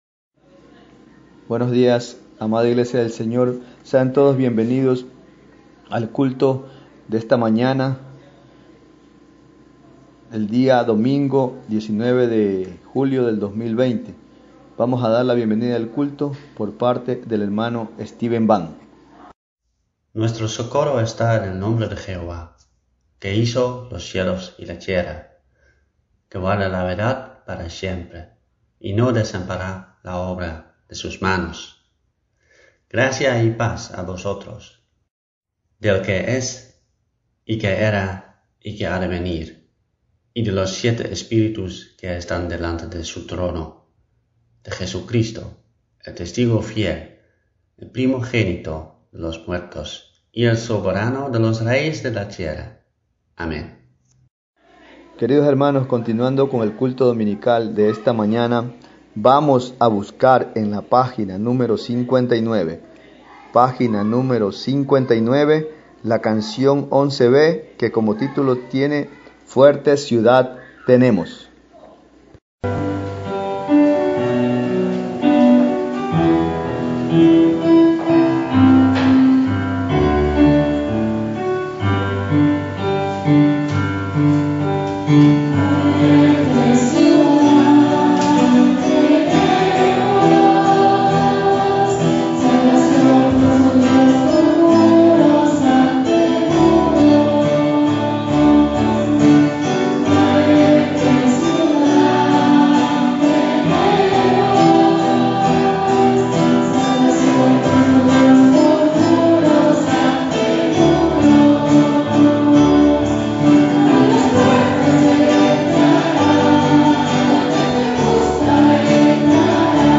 Tipo: Sermón